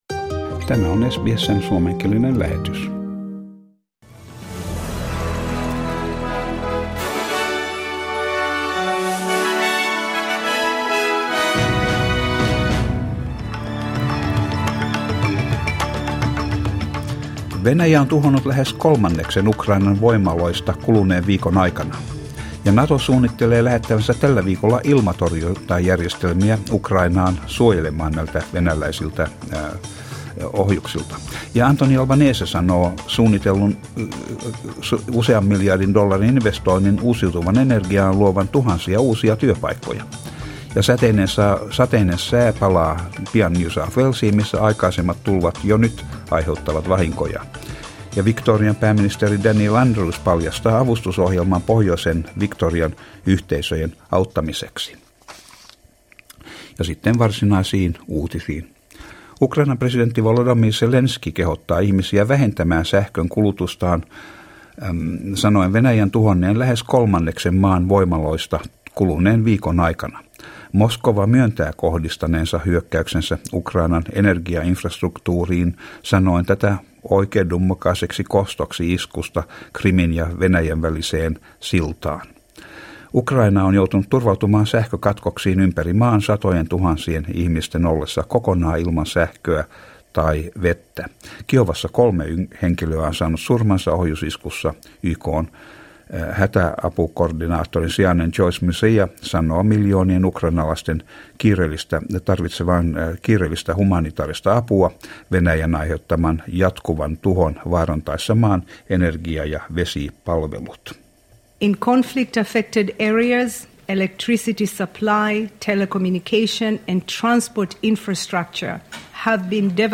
Suomenkieliset uutiset Source: SBS